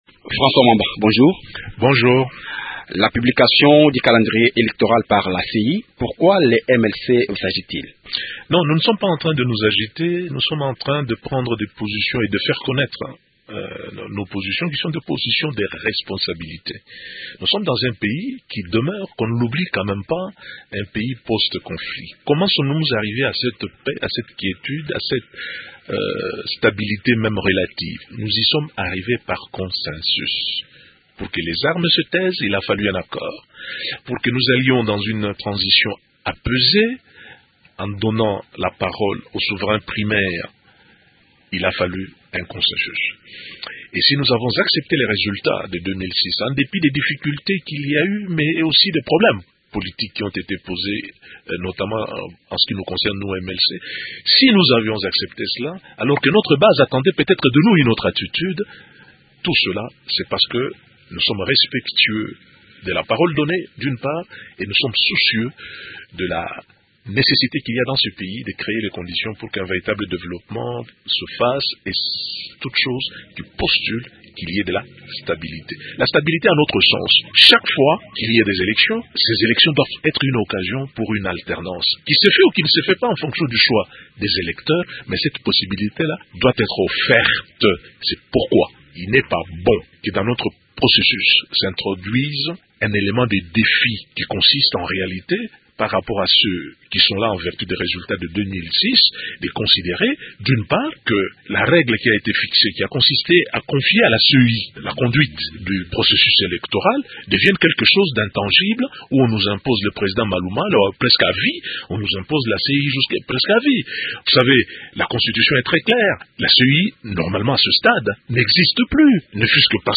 François Mwamba, Secretaire général du MLC